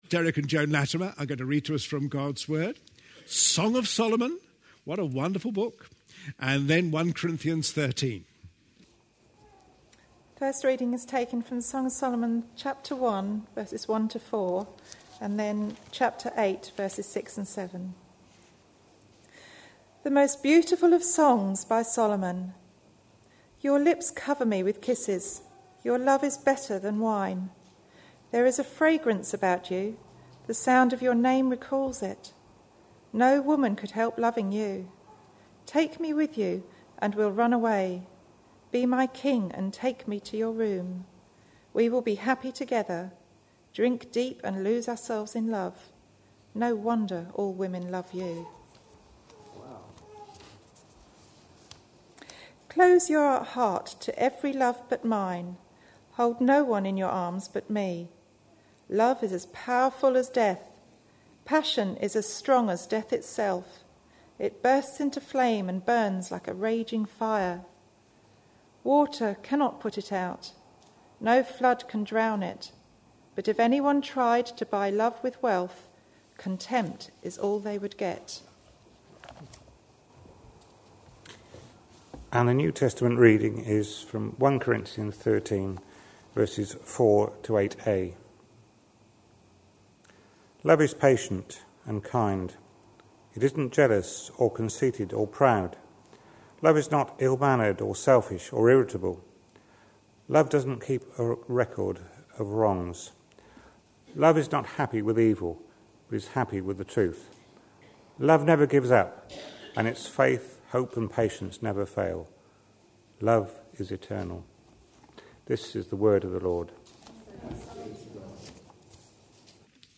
A sermon preached on 11th March, 2012, as part of our Looking For Love (10am Series) series.